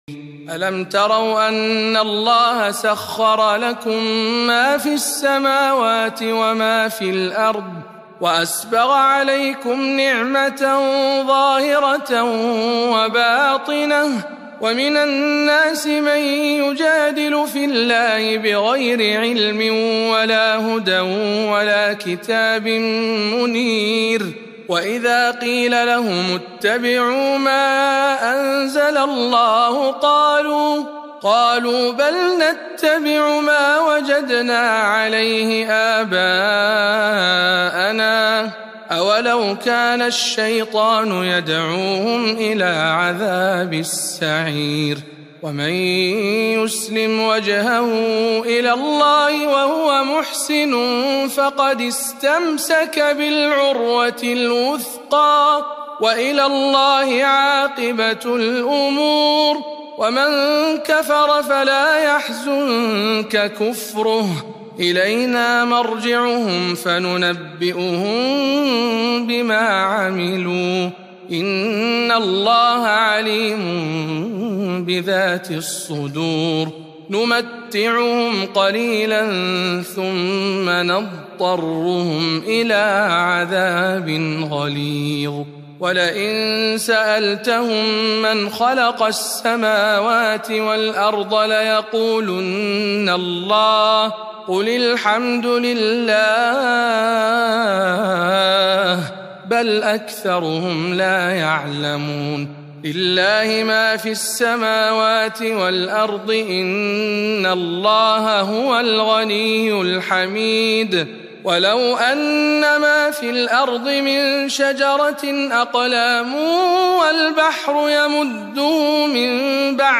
تلاوة مميزة من سورة لقمان